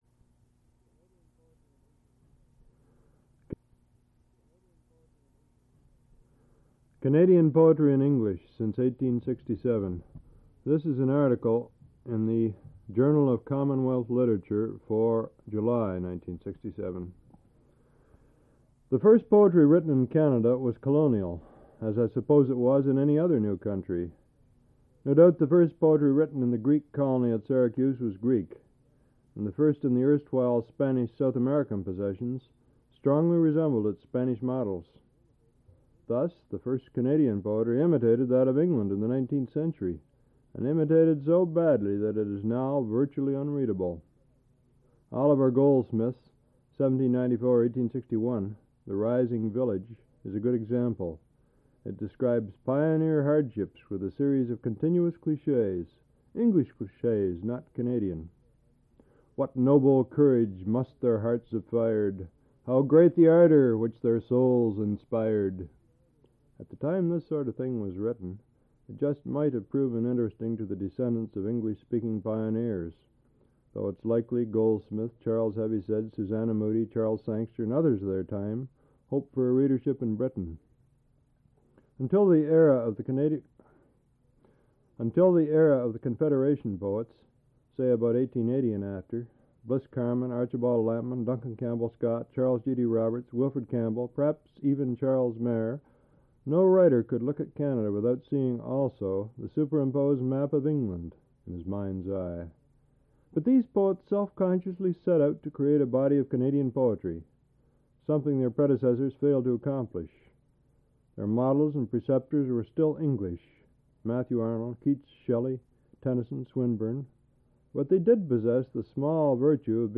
Al Purdy Reads an essay about Canadian Poetry